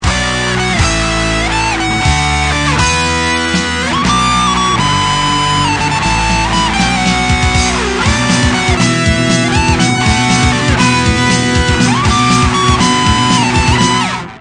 １音間違えているけど誤魔化した。